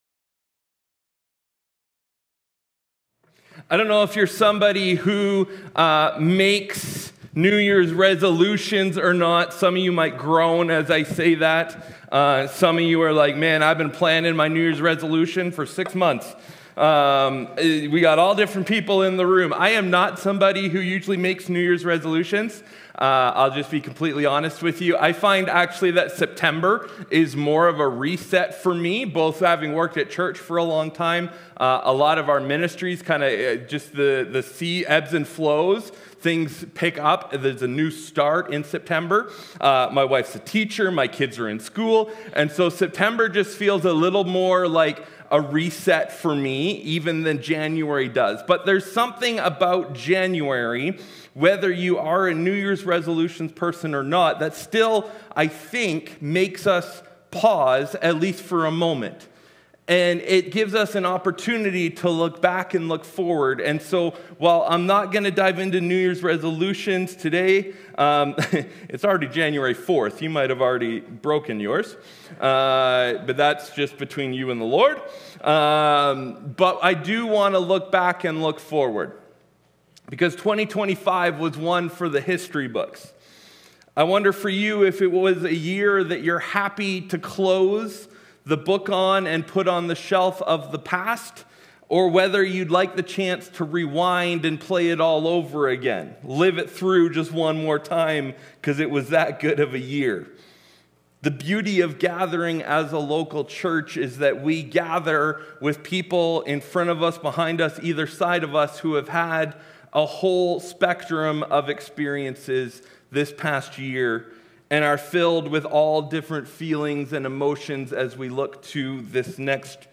Sunday Service Jan 4, 2026.mp3